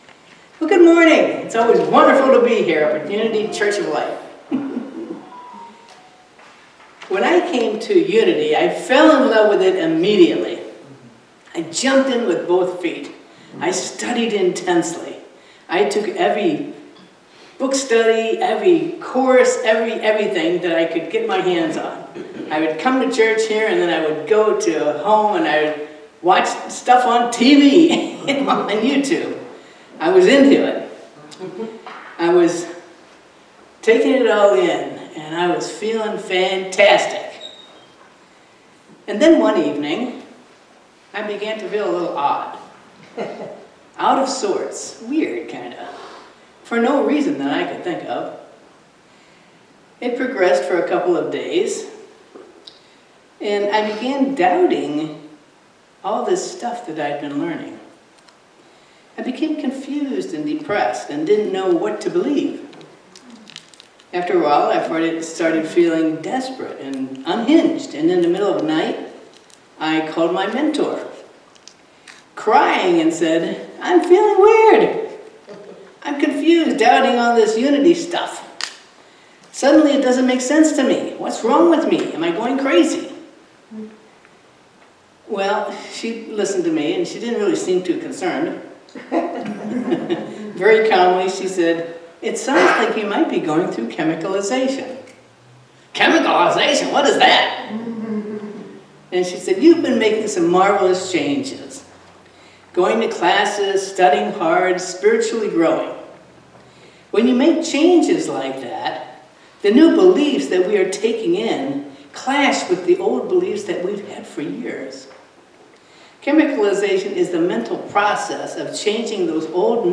Series: Sermons 2025